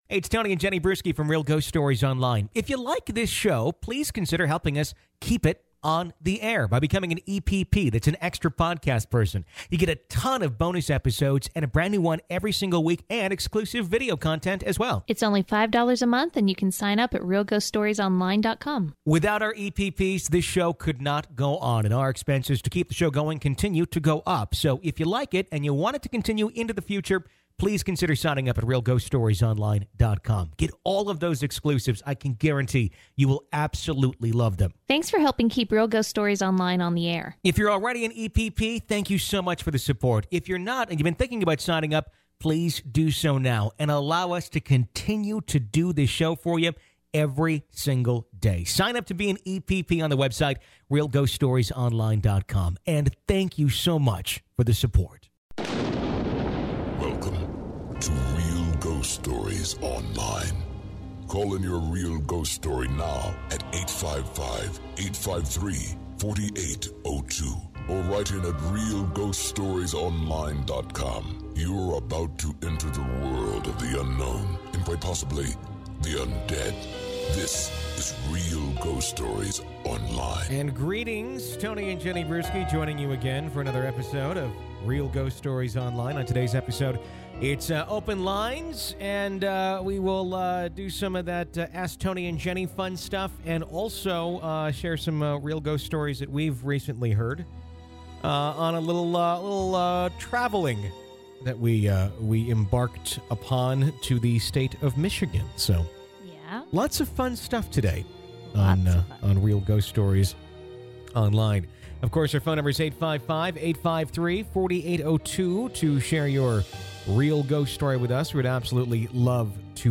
take real ghost story calls during open lines